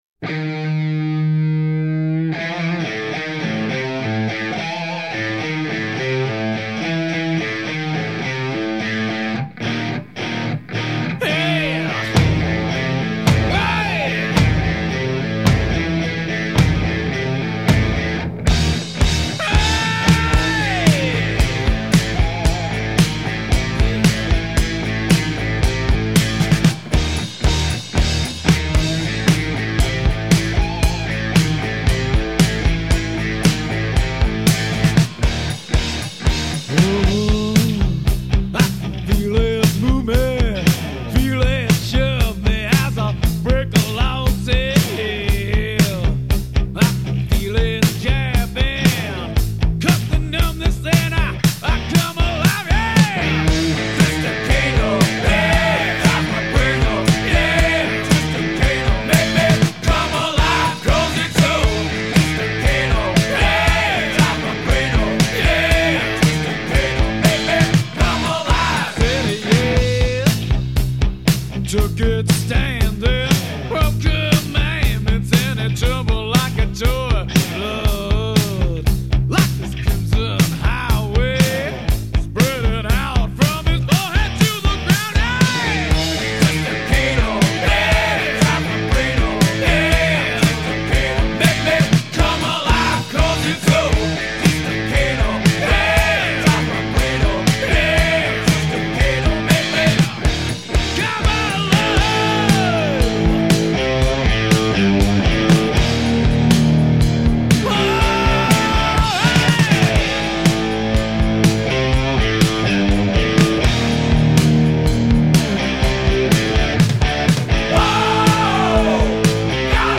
Рок музыка Hard Rock Heavy Metal